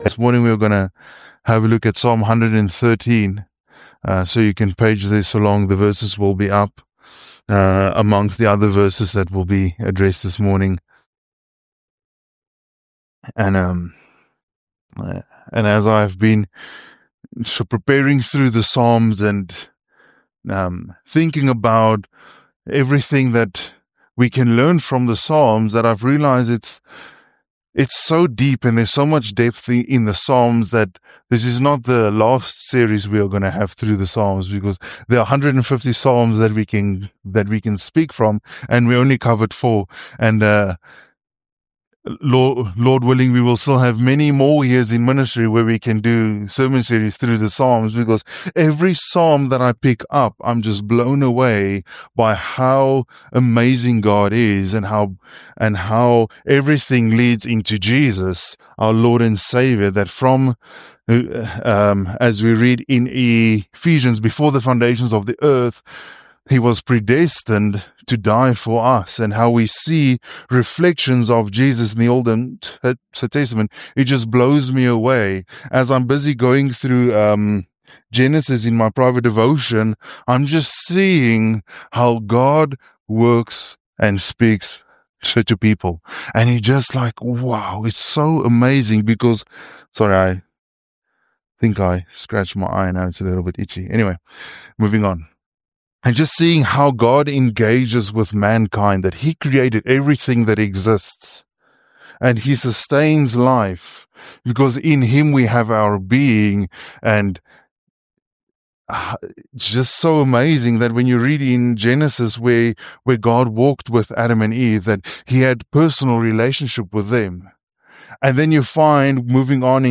GBC Podcast to share audio sermons and talks.